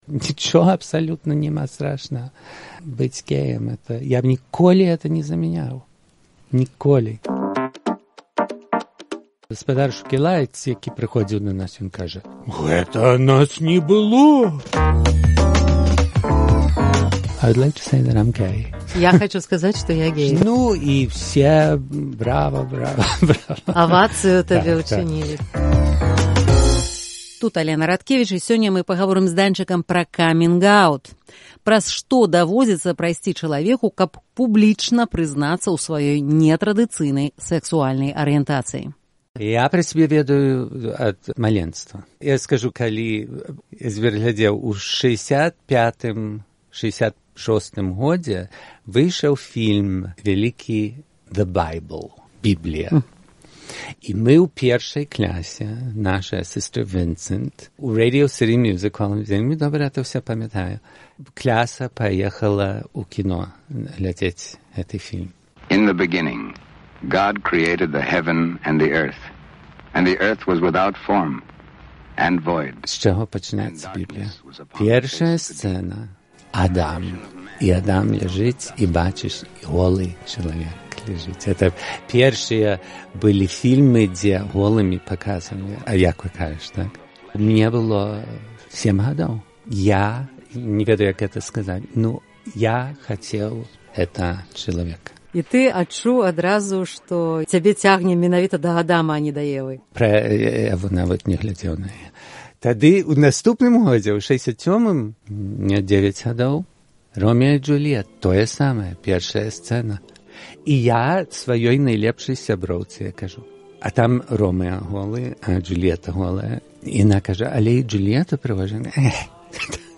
Культавы сьпявак для Беларусі 90-х Багдан Андрусышын (Данчык) распавядае, праз што яму давялося прайсьці, каб публічна прызнацца ў сваёй сэксуальнай арыентацыі, а таксама расказвае анэкдот, які ходзіць у гей-супольнасьці – пра тое, як адкрыцца сваім бацькам.